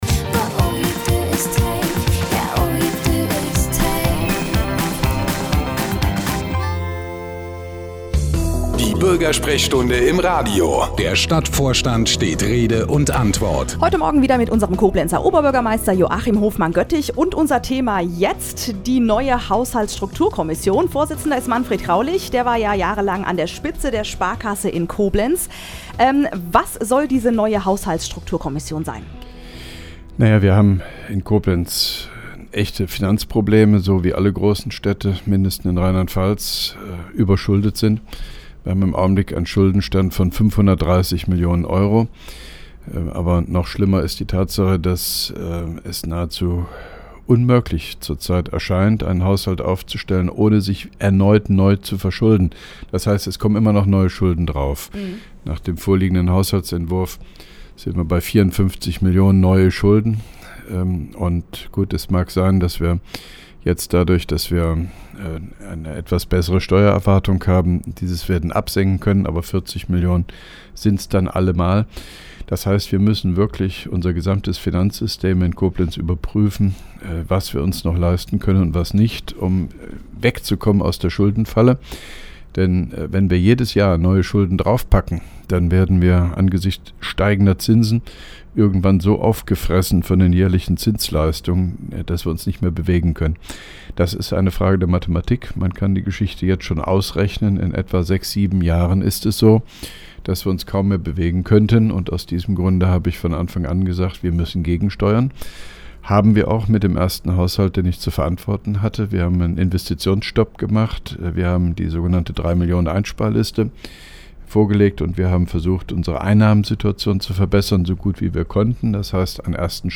(3) Koblenzer Radio-Bürgersprechstunde mit OB Hofmann-Göttig 12.07.2011
Interviews/Gespräche